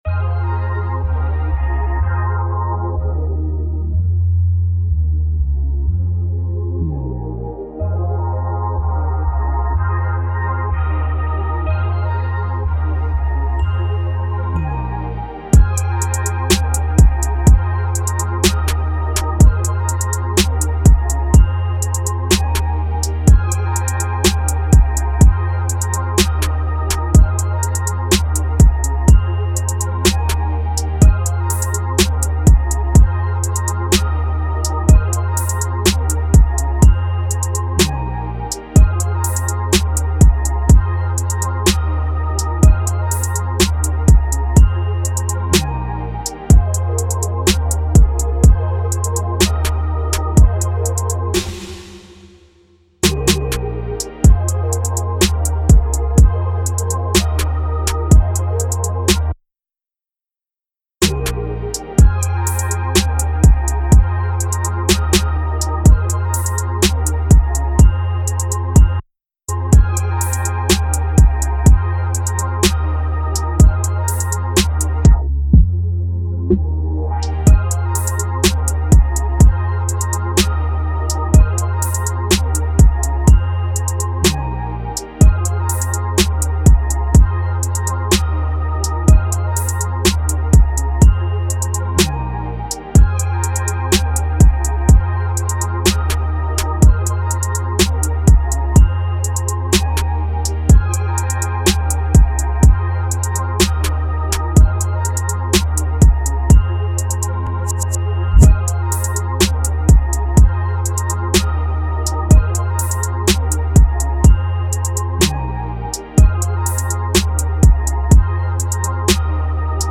Reggae Instrumentals